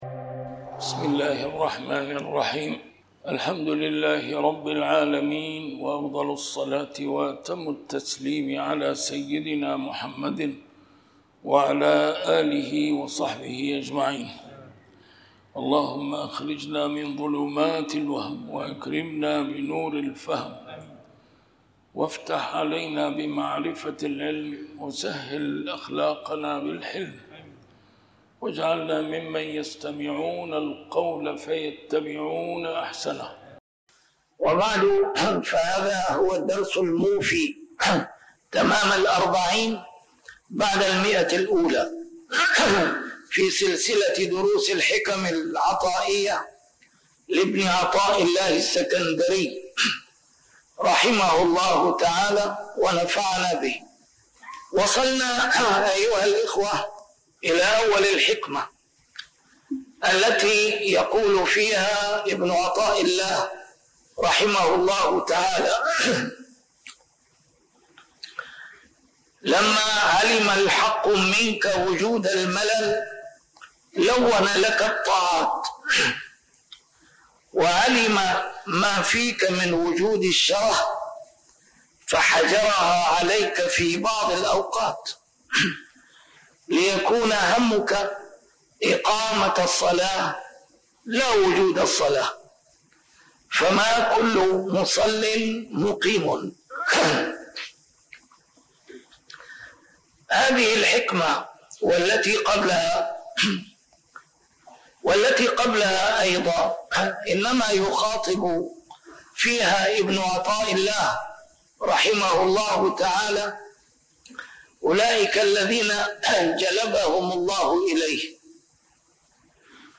A MARTYR SCHOLAR: IMAM MUHAMMAD SAEED RAMADAN AL-BOUTI - الدروس العلمية - شرح الحكم العطائية - الدرس رقم 140 شرح الحكمة 118